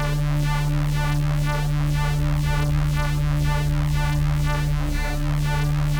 Index of /musicradar/dystopian-drone-samples/Tempo Loops/120bpm
DD_TempoDroneD_120-D.wav